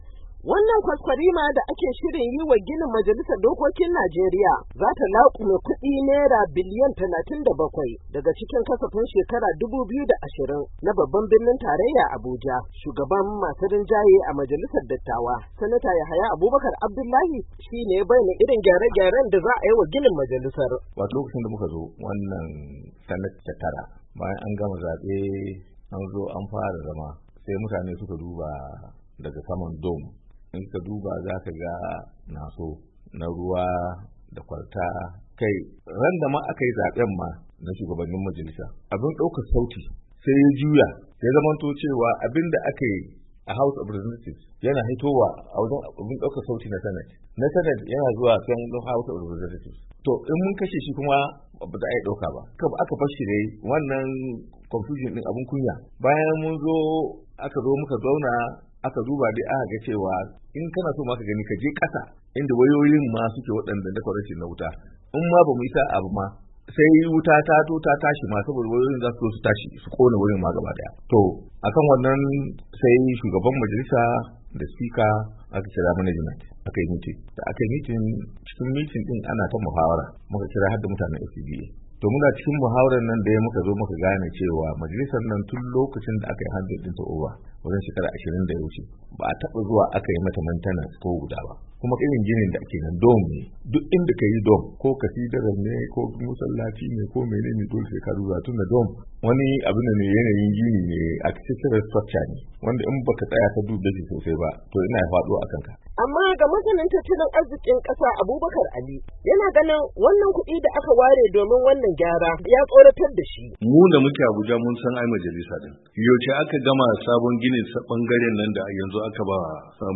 Ga rohoto cikin sauti.